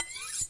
厨房 " 抛光厨房刀 1
描述：抛光菜刀（1/4）。
Tag: 厨房 抛光